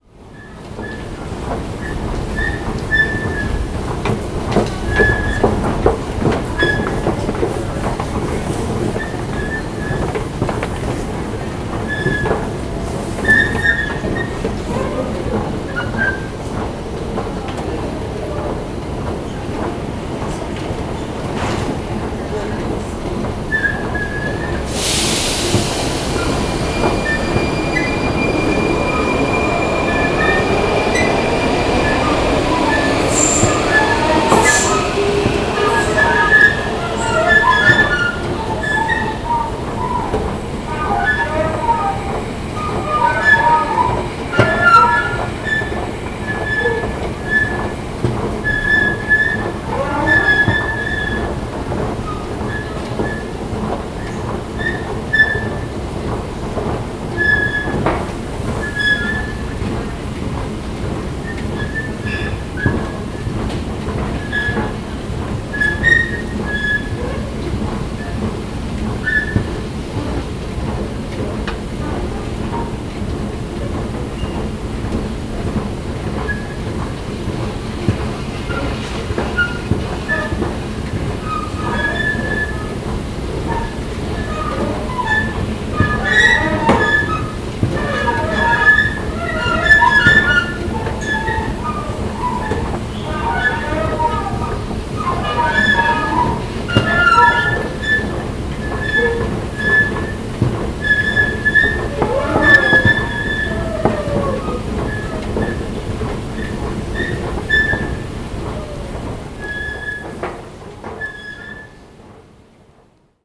Singing escalator on the Můstek station | Zvuky Prahy / Sounds of Prague
field recordings, sound art, radio, sound walks
Zpívající eskalátor v metru na Můstku
Tagy: stroje podzemí hromadná doprava
Proto dochází rychle k opotřebování hnacího mechanismu a zmíněné skřípání je symptom takového opotřebení a zároveň projec hudebního talentu rodiny Eskalátorů.
eskalatorymetromustek.mp3